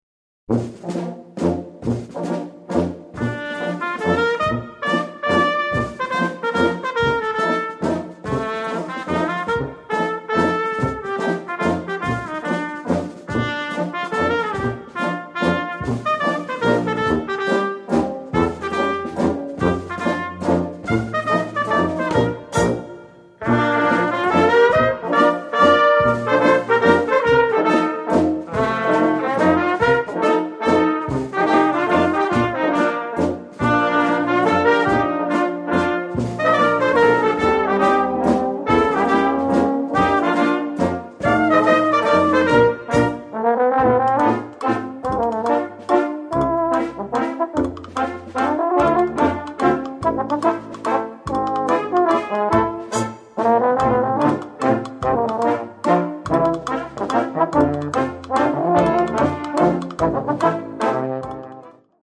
Ragtimes, valses & bostons